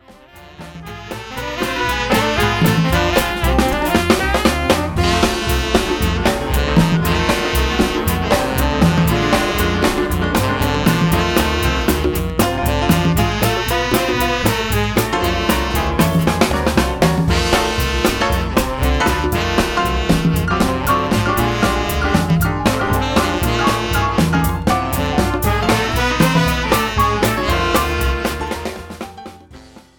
bass
piano